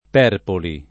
[ p $ rpoli ]